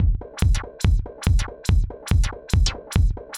Index of /musicradar/uk-garage-samples/142bpm Lines n Loops/Beats
GA_BeatAFilter142-06.wav